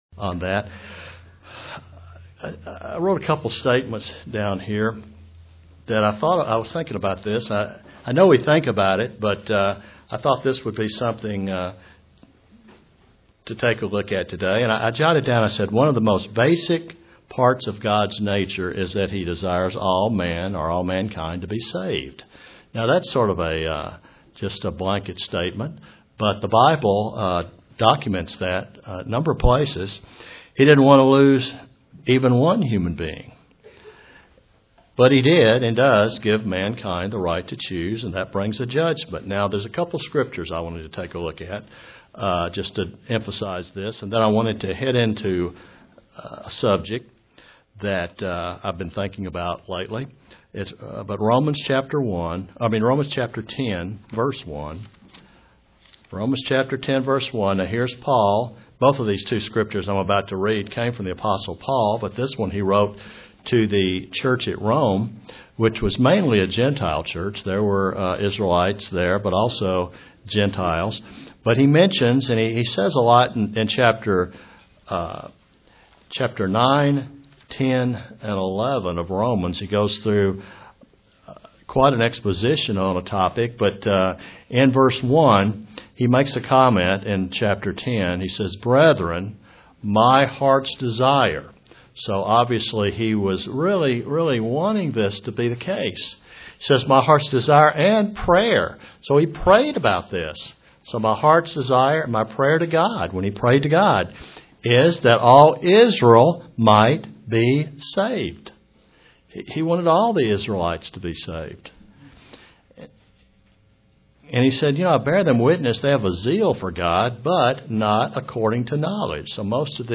Satan is the adversary but he cannot thwart God’s plan of salvation. (Presented to the Kingsport TN, Church)